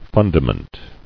[fun·da·ment]